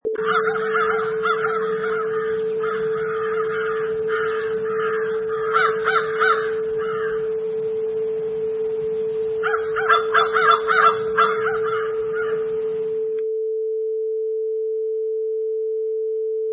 Sound: Crows 2
Multiple crows far and wide calling and cawing
Product Info: 48k 24bit Stereo
Category: Animals / Birds
Try preview above (pink tone added for copyright).
Crows_2.mp3